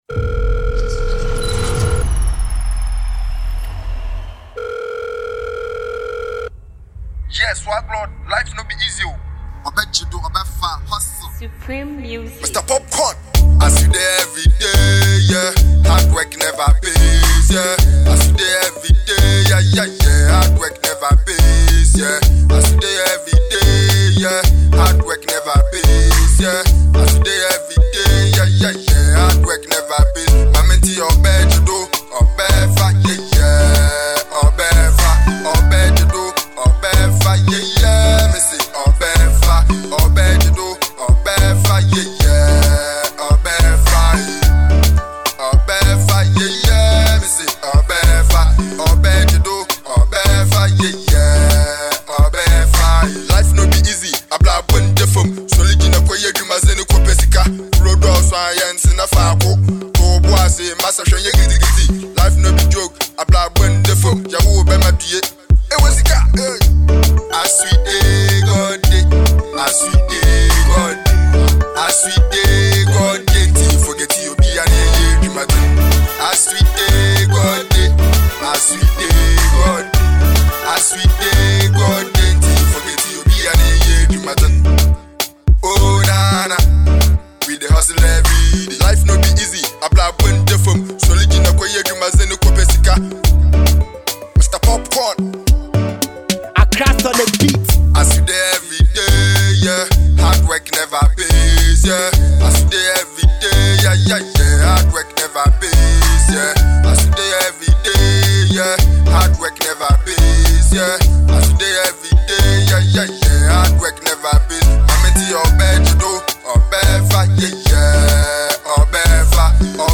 motivational tune